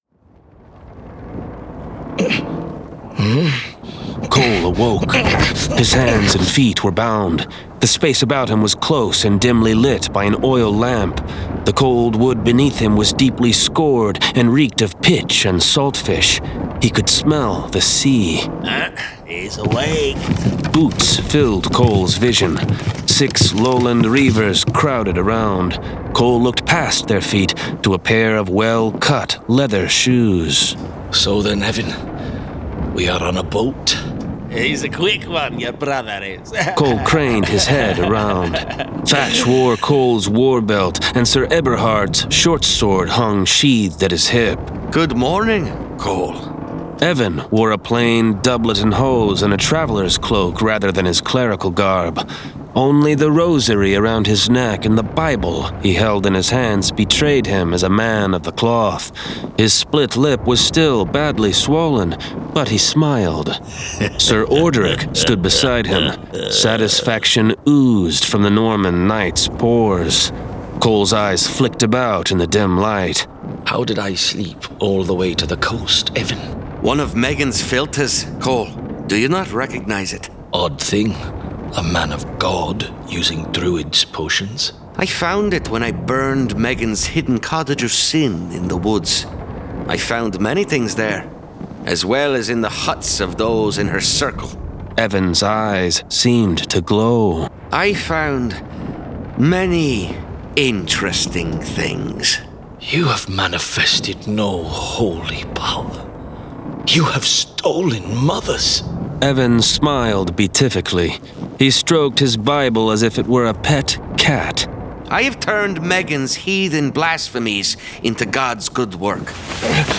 Heroes Road: Volume Two 1 of 3 [Dramatized Adaptation]